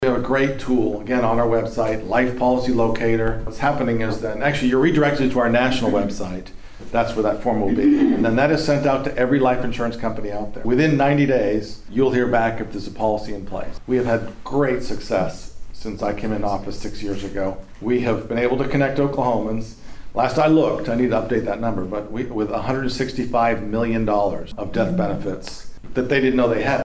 The Pawhuska Chamber of Commerce hosted the April lunch and learn series, which featured Oklahoma Insurance Commissioner Glen Mulready as the speaker.